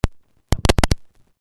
Звуки аудио-колонки
Звук подключения активной колонки в аудиосистеме